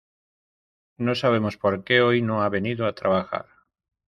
Read more Det Adv Pron Frequency A1 Pronounced as (IPA) /ˈke/ Etymology Inherited from Latin quid In summary Inherited from Latin quid.